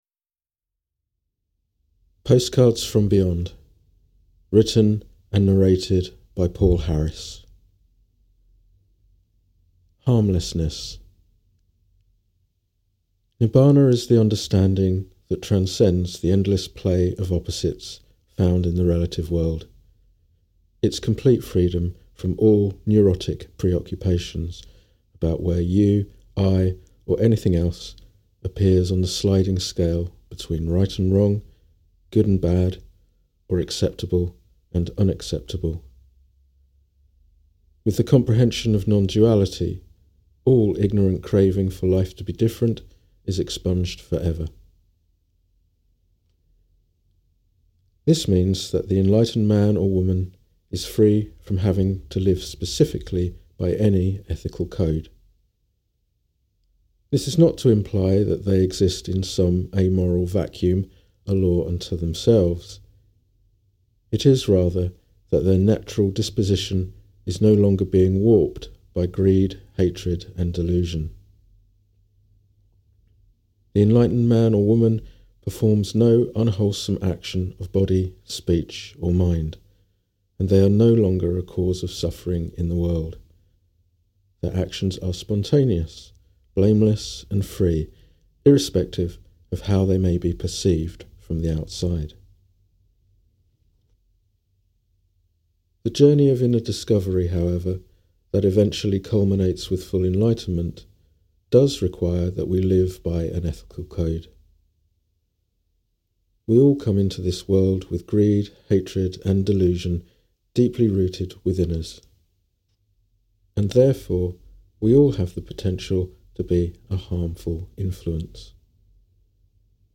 Audio recording of the book "Postcards from Beyond"